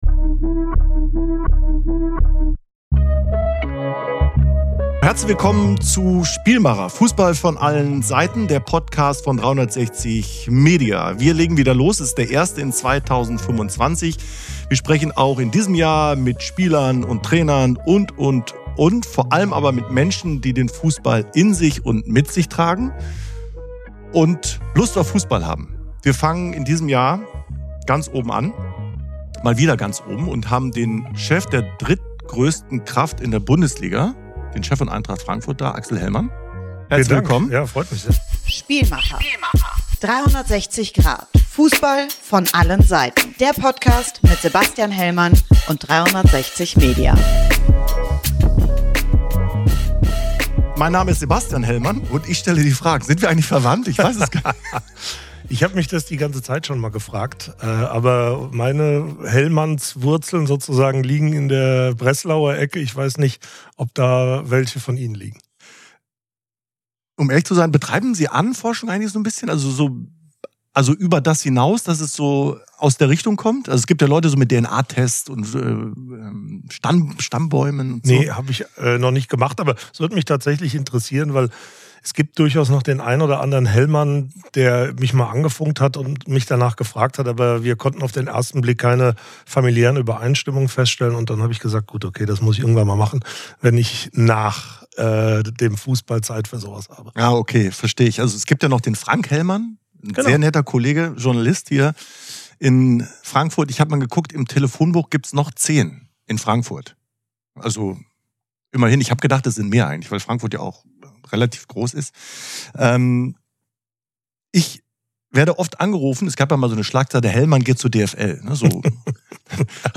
Beschreibung vor 1 Jahr Zum Start ins neue Jahr ist der Chef der drittgrößten Kraft der Bundesliga zu Gast: Axel Hellmann, Vorstandssprecher von Eintracht Frankfurt und Präsidiumsmitglied der DFL. Axel Hellmann blickt auf ein erfolgreiches Jahr 2024 und analysiert, was es braucht, damit die Eintracht diese Saison erfolgreich zu Ende spielen kann. Er gibt Einblicke in die Transferpolitik der Frankfurter rund um Topspieler Omar Marmoush und erklärt, wieso er wenig von Ausstiegsklauseln hält.